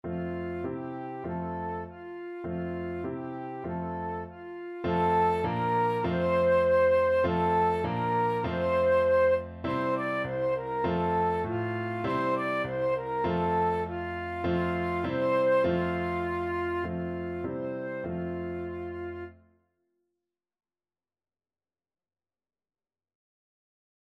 Flute
F major (Sounding Pitch) (View more F major Music for Flute )
Traditional (View more Traditional Flute Music)